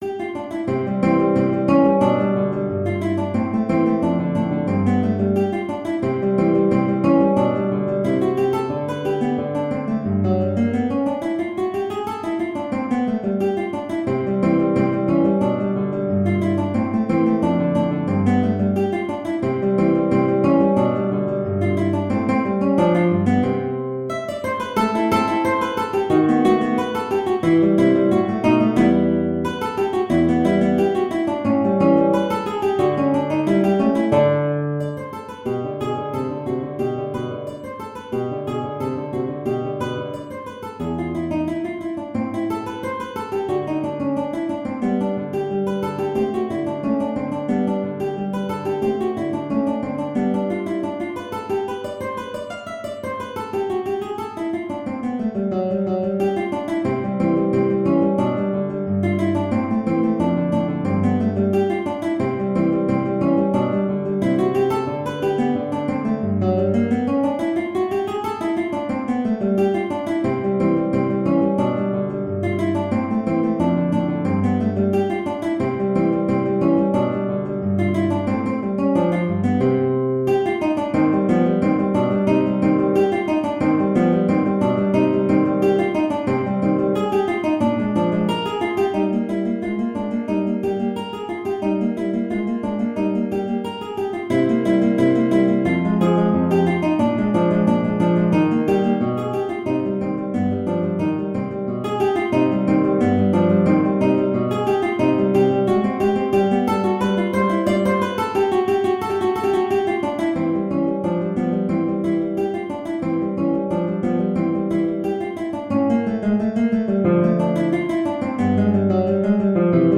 C major (Sounding Pitch) (View more C major Music for Guitar )
Allegretto = 90
2/4 (View more 2/4 Music)
E3-F6
Guitar  (View more Intermediate Guitar Music)
Classical (View more Classical Guitar Music)